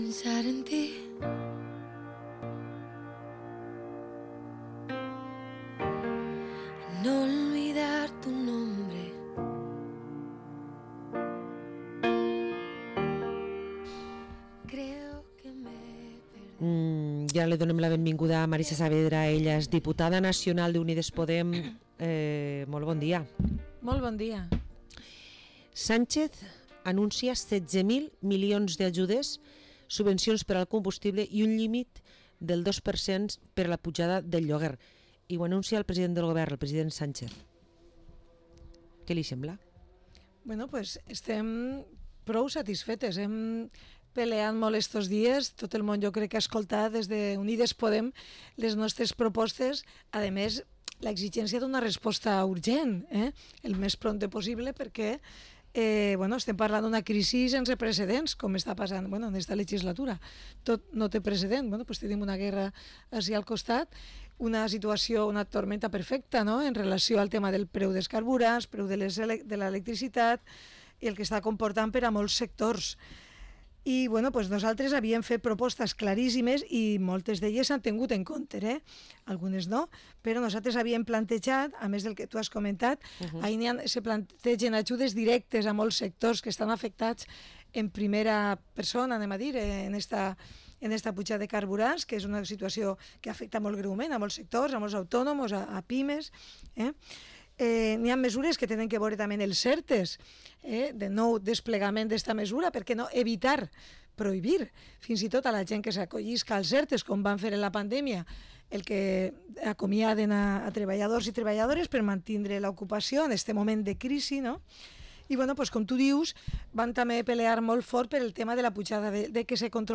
Entrevista a la diputada nacional de Unidas Podemos, Marisa Saavedra